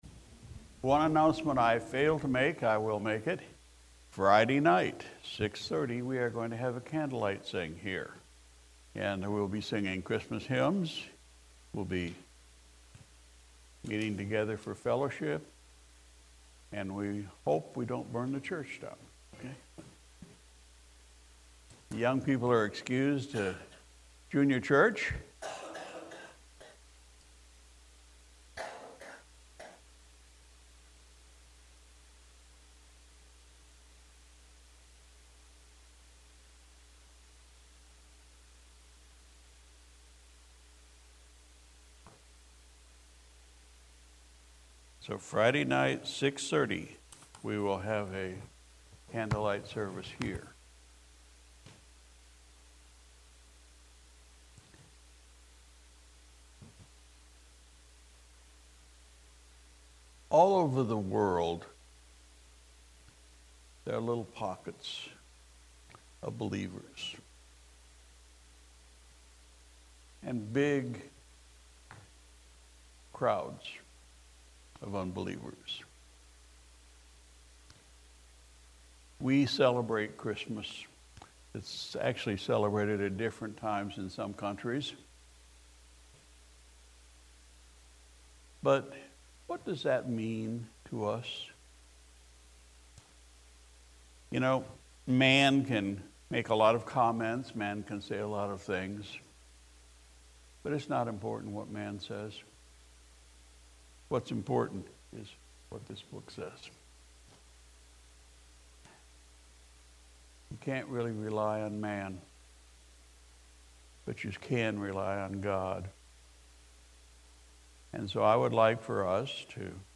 December 18, 2022 Sunday Morning Service Pastor’s Message “What Does This Mean?”